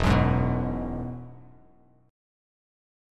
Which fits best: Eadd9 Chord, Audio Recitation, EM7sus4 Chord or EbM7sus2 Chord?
Eadd9 Chord